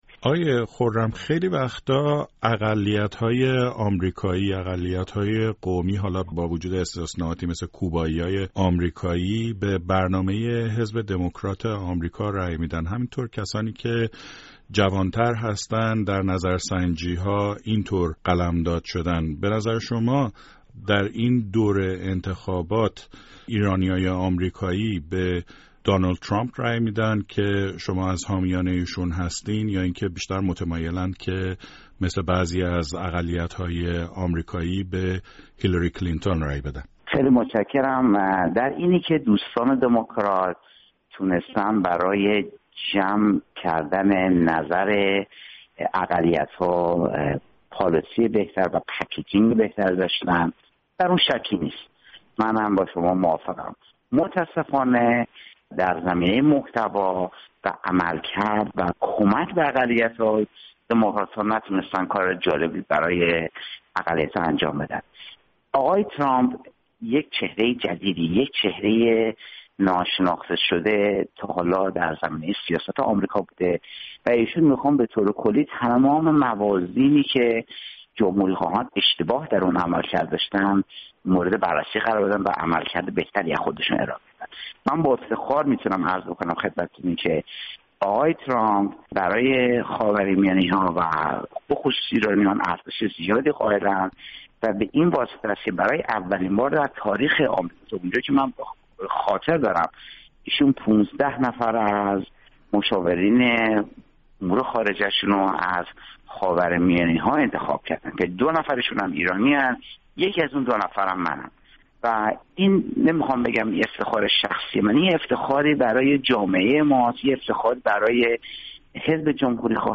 مناظره دو ایرانی-آمریکایی؛فعال باسابقه حزب دمکرات مقابل مشاور انتخاباتی ترامپ